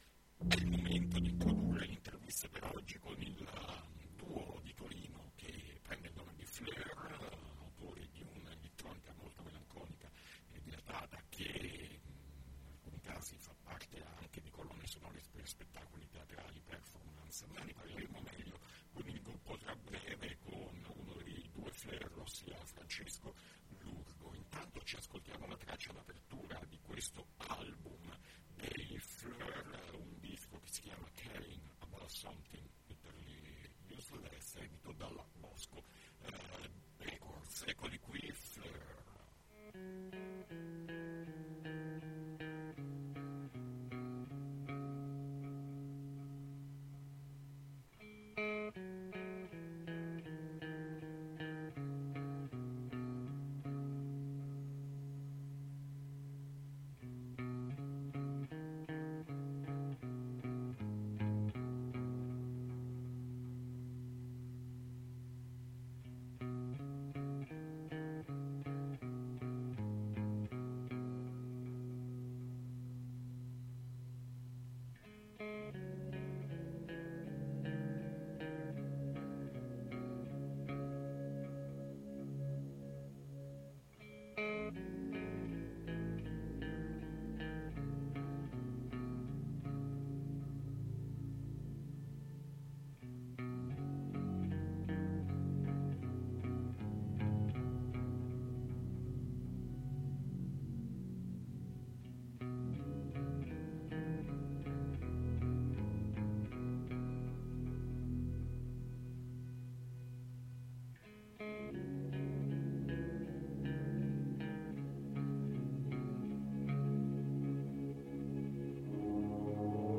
Intervista FLeUR